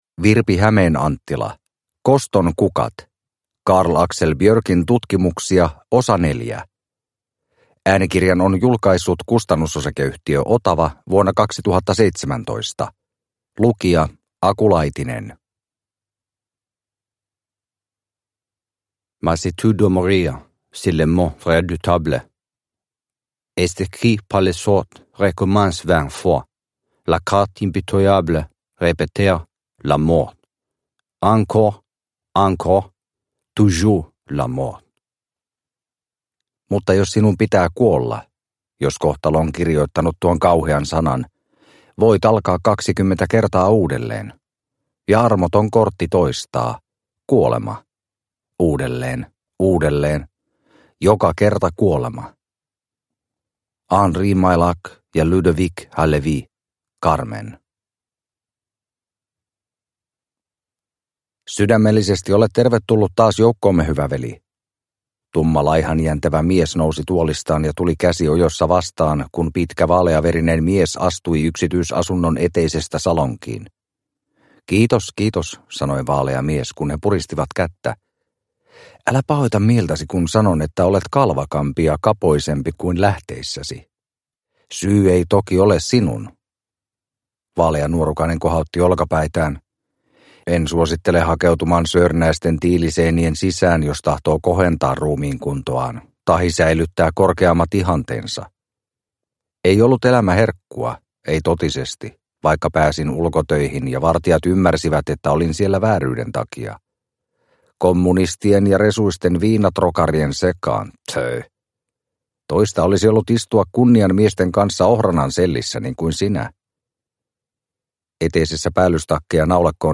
Koston kukat – Ljudbok – Laddas ner